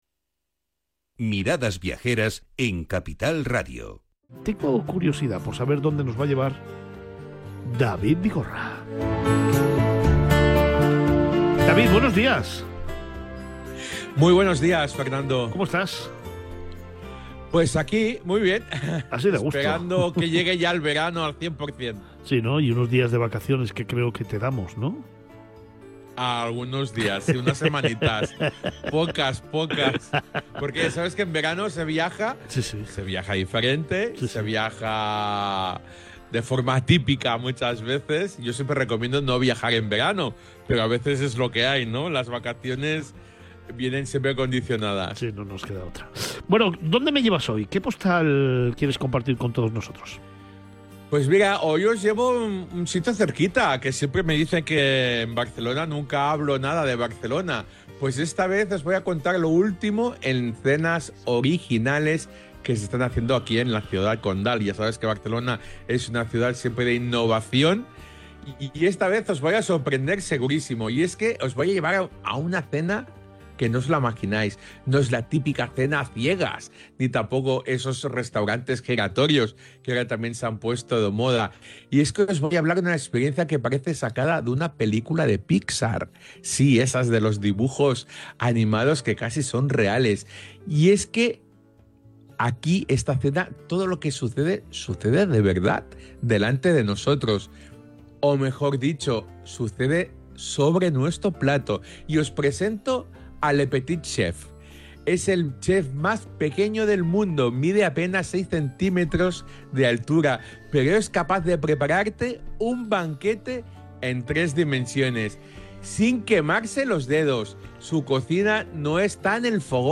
Hoy en el programa «Miradas Viajeras» de Capital Radio os voy a contar lo último en cenas originales que se está haciendo en Barcelona.